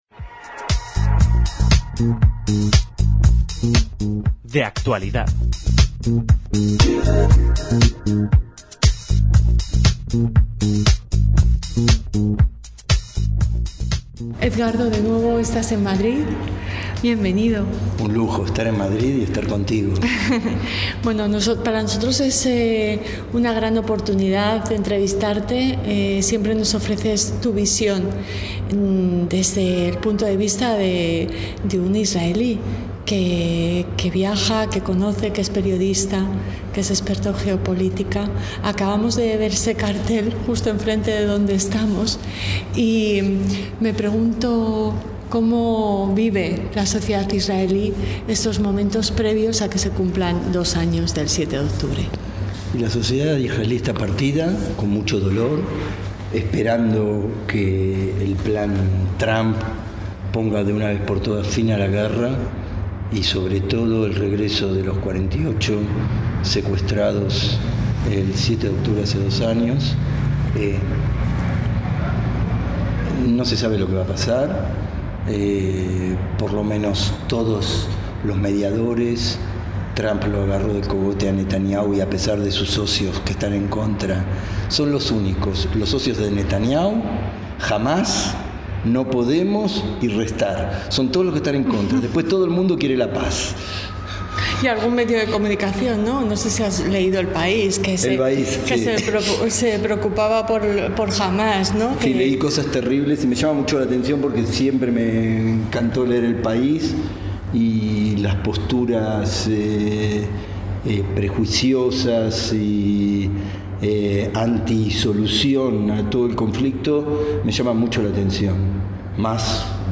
en Madrid, casi bajo un cartel que exige la libertad de Palestina.
esta entrevista